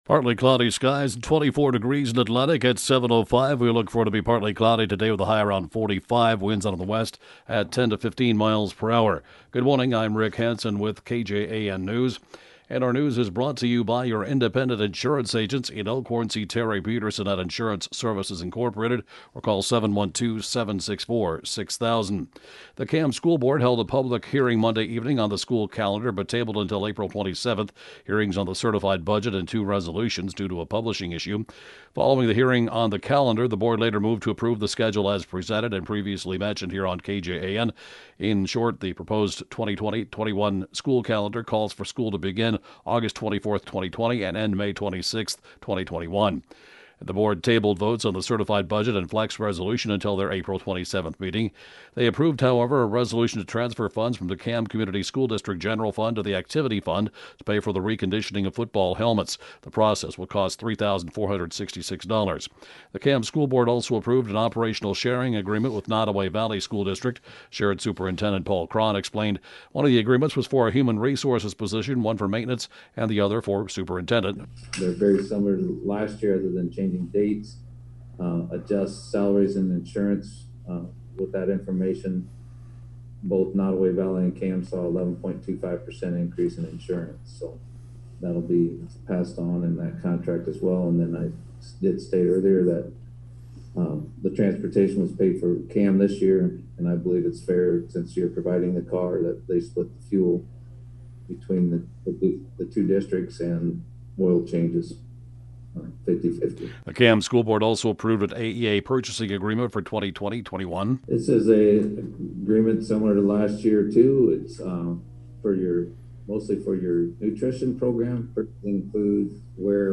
The area’s latest and/or top news stories at 7:05-a.m.